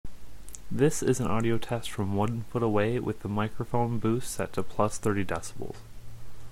My Mic has background noise.
I just purchased a MXL 770 running into a InnoGear 1- Channel 48V Phantom Power Supply, that then feeds into my on board sound card via 3pin XLR Female to 1/4" 6.35mm Mono Male Adapter into a 1/8 inch Male to 1/4 inch Female Audio Jack Adapter.
It wasn't working at all on one of my computers so I tried on another with better success but I'm getting a lot of background noise, is this normal?